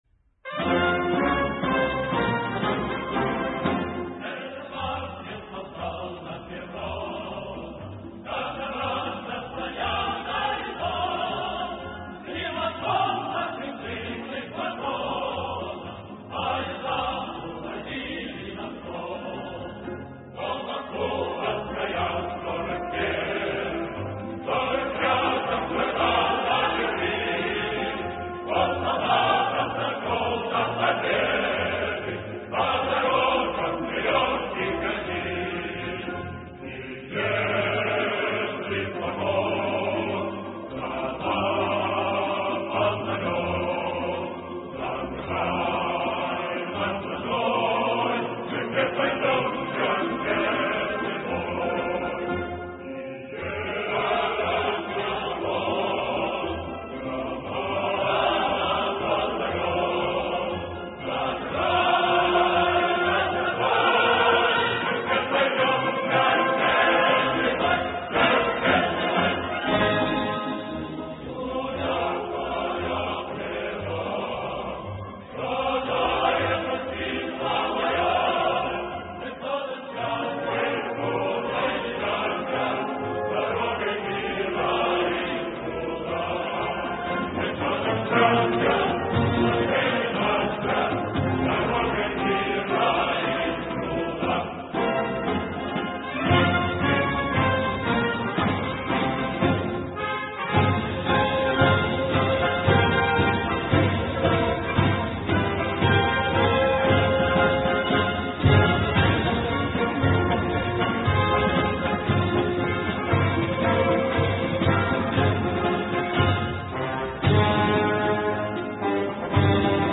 песня-марш